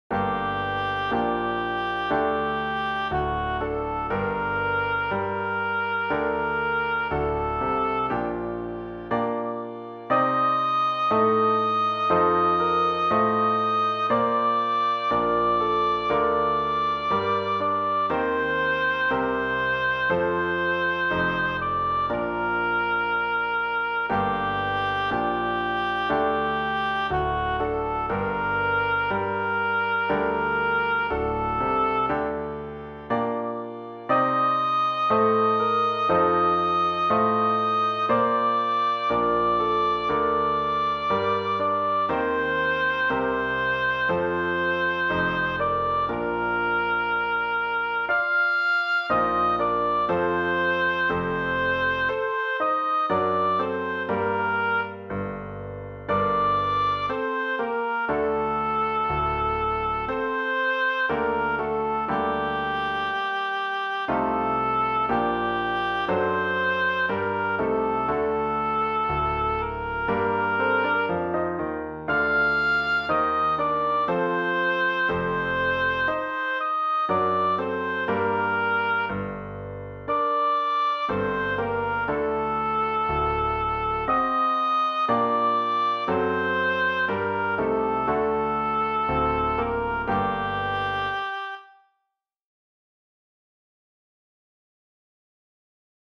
A.A. 24/25 Canto Corale
O-Dianne-Gluck-SOP-SOL-m.mp3